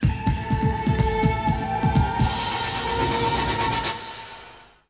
Title Theme | Jake | Cassie | Ax | Tobias ]